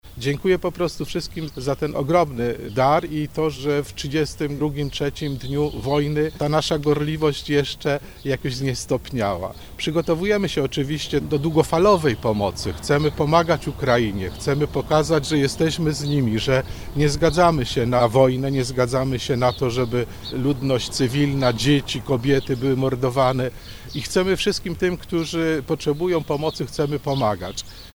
– To wielki dar serca i wyraz solidarności lekarzy – mówi abp Józef Kupny